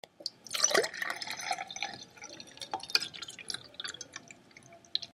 litivodydosklenice.mp3